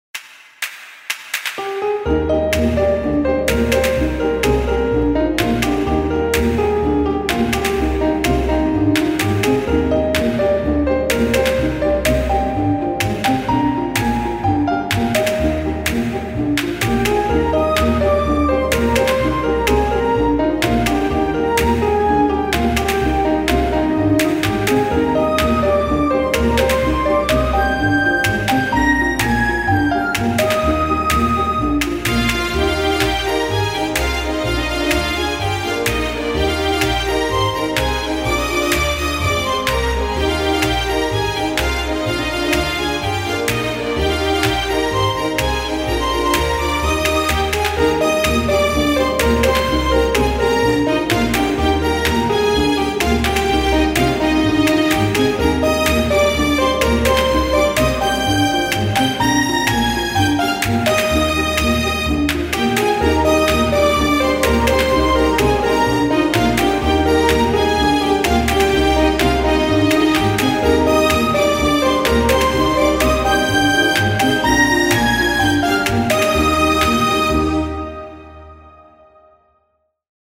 オーケストラ調の楽しそうなポルカ（チェコの舞曲）風のBGMです。くるくる回るような、元気が出る曲です。